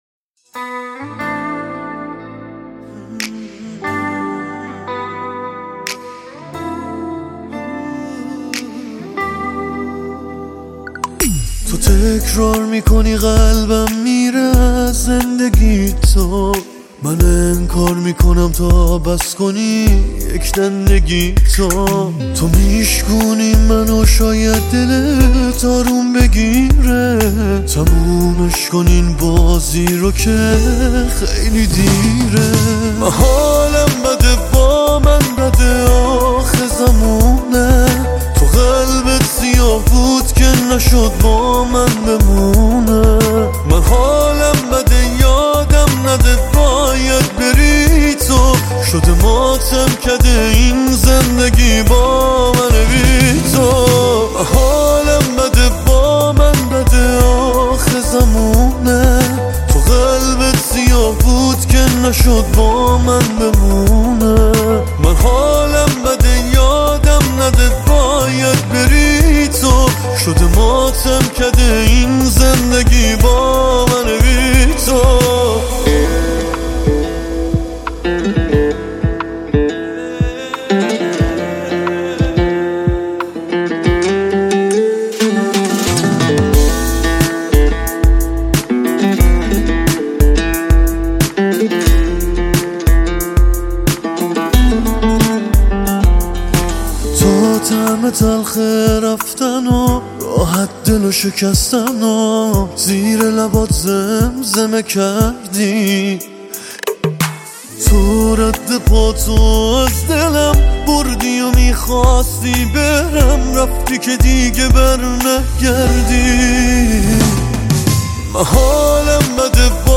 پاپ غمگین عاشقانه غمگین